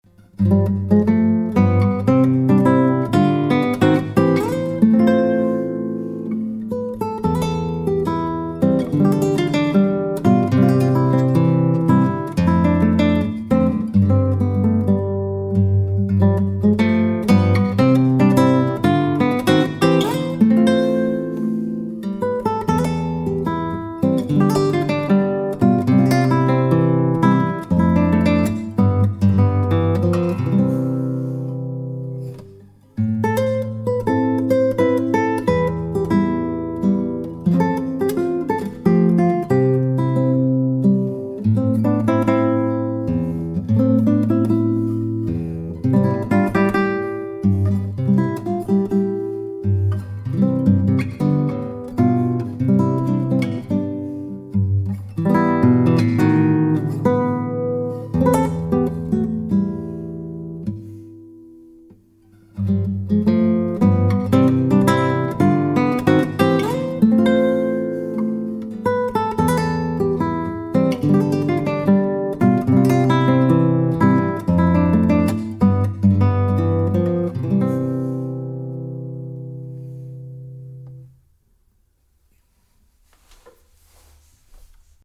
Mikrofon für klassische Gitarre
Ich habe mal die "ohne Hall"-Variante aus Post #44 genommen. da stand das Mikro ähnlich wie bei den letzten Beispielen, also mehr rechts vom Steg...